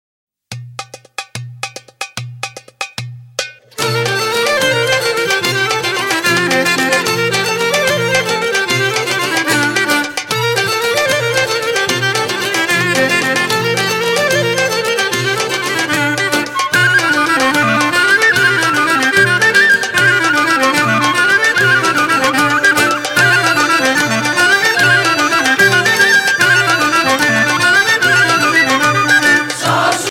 Σύλλογος παραδοσιακών χορών-χορωδίας